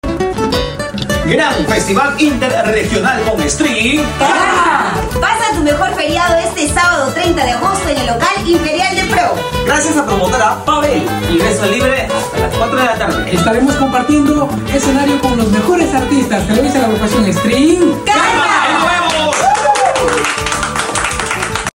con lo mejor de la música cajamarquina!